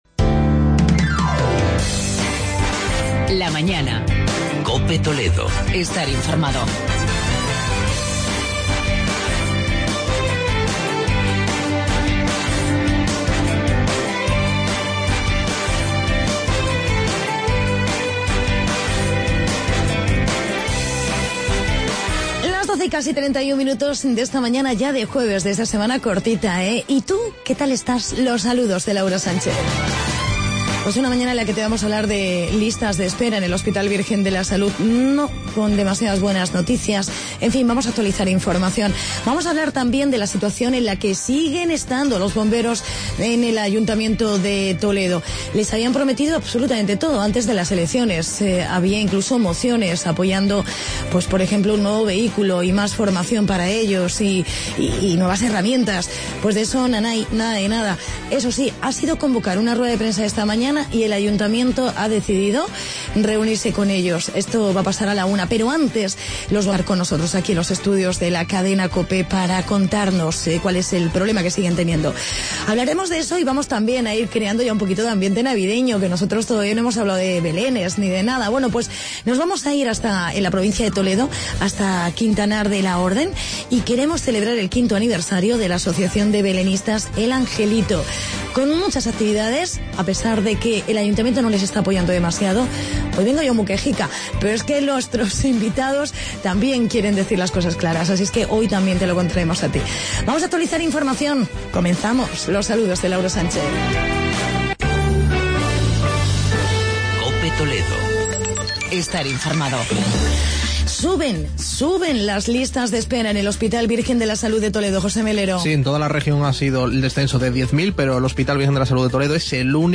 Entrevista con los sindicatos de bomberos de Toledo